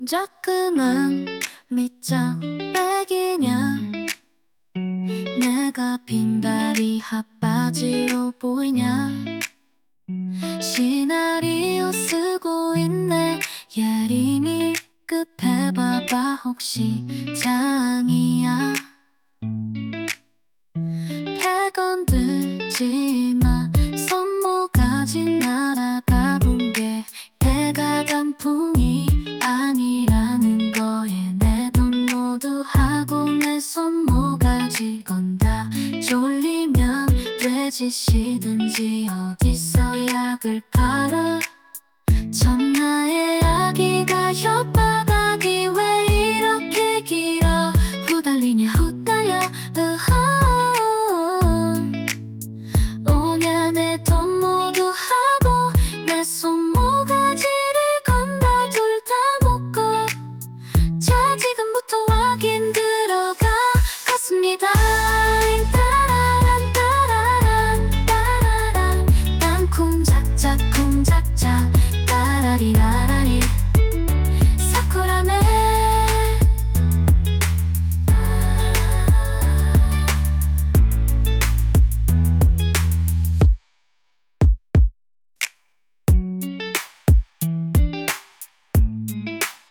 여자 Kpop 아이돌 버전 <동작그만 밑장빼기냐?>